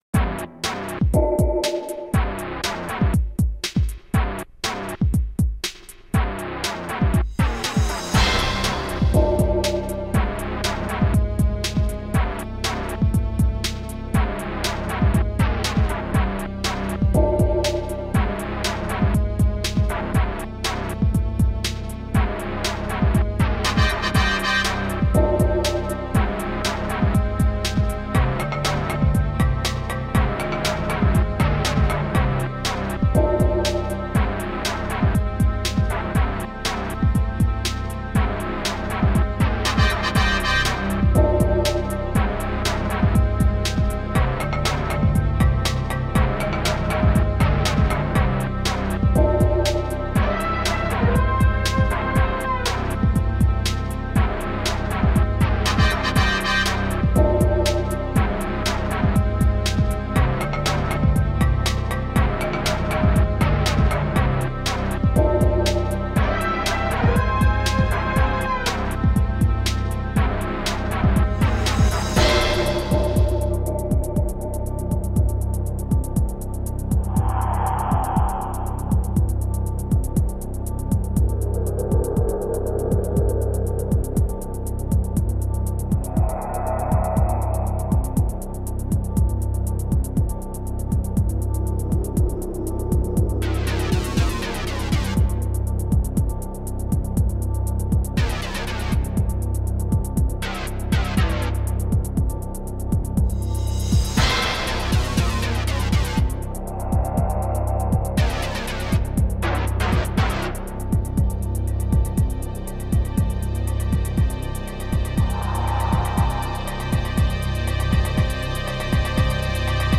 BPM60-120
Audio QualityPerfect (High Quality)